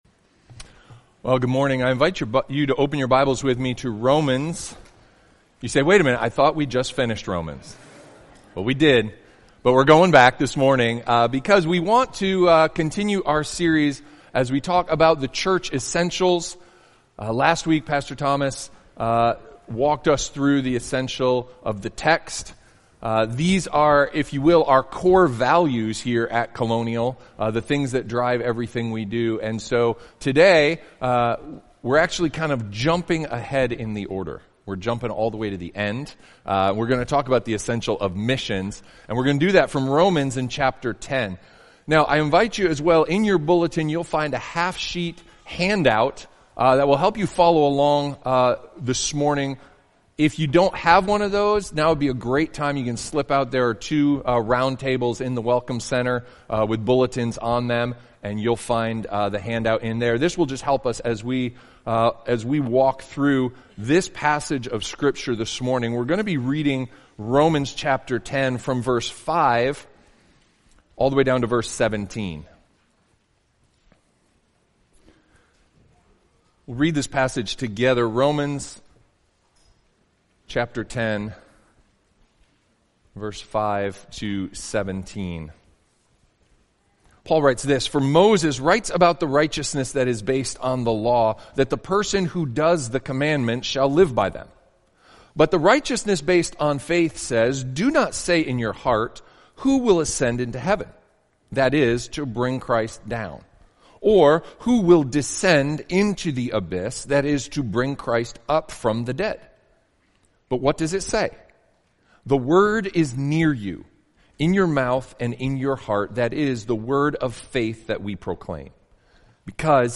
preaches on Romans 10:5-17.